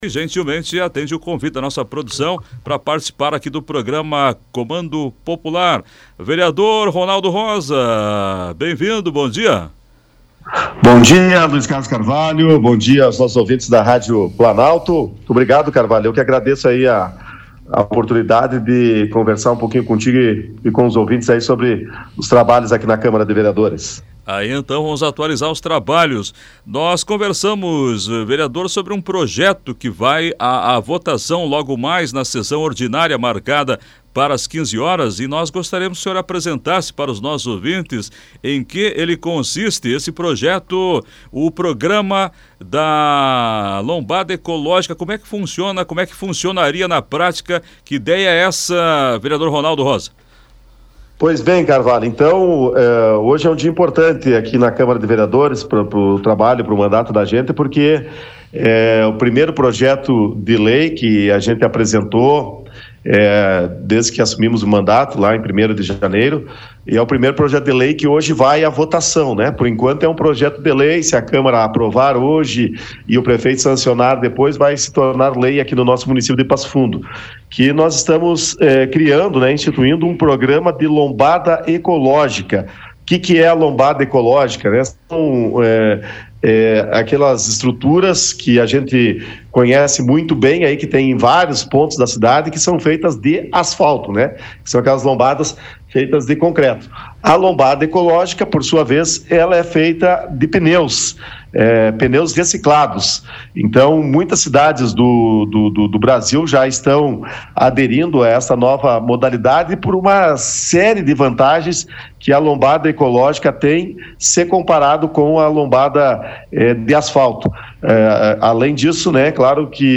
SOBRE A IMPORTÂNCIA DO PROJETO, O VEREADOR RONALDO ROSA CONCEDEU ENTREVISTA À PLANALTO NEWS.